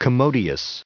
added pronounciation and merriam webster audio
914_commodious.ogg